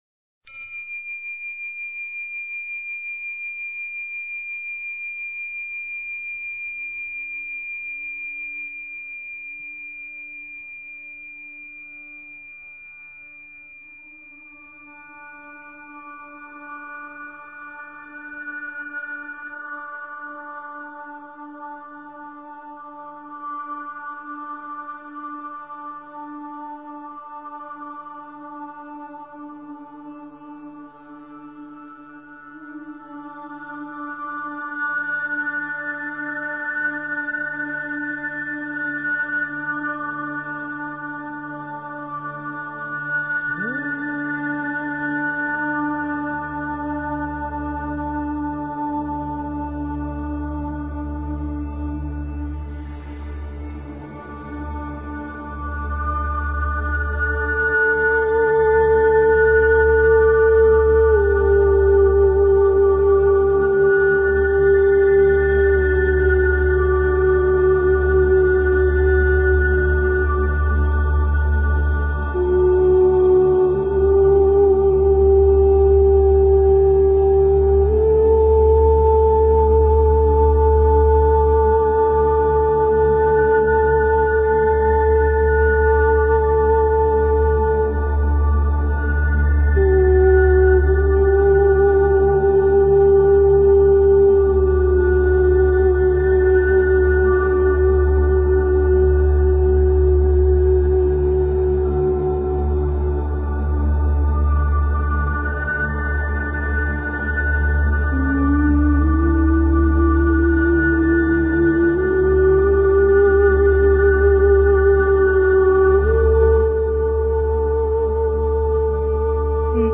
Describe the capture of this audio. This is a call in show so call in!